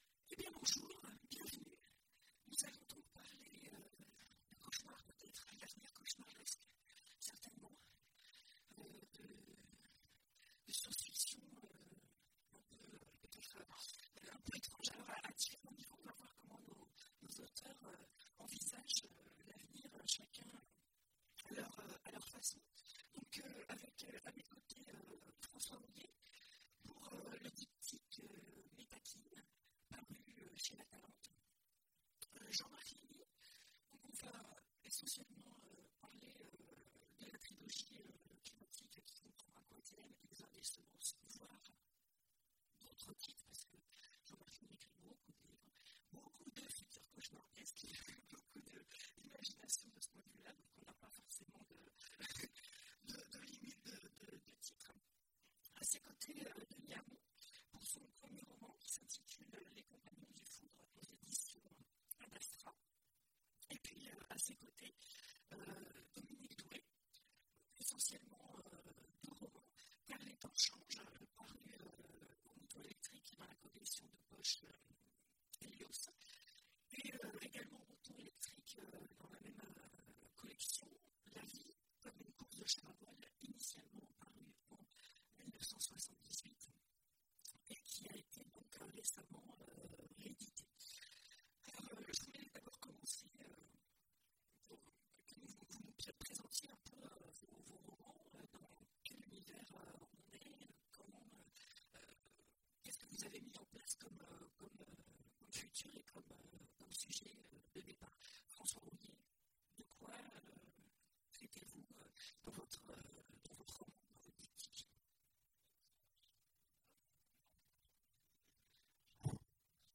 Imaginales 2016 : Conférence Futurs à cauchemarder grave…